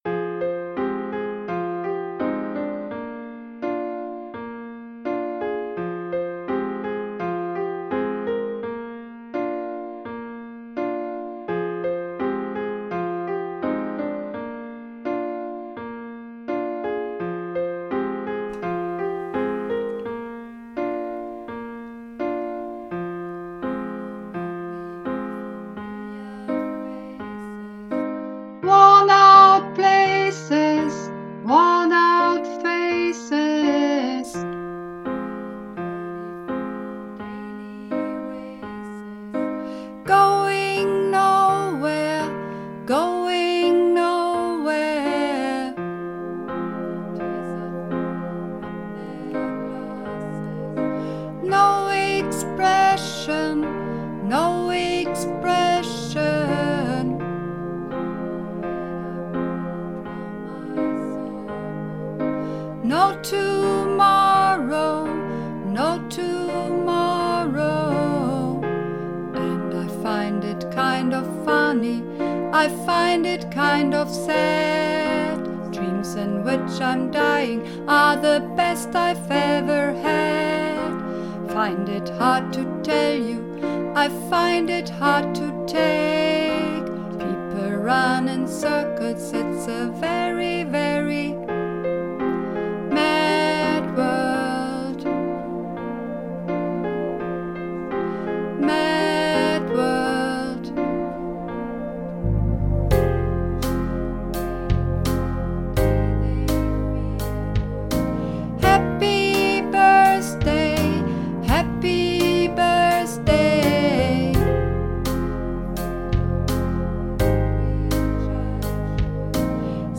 Mad World (Sopran)
Mad_World__3_Sopran.mp3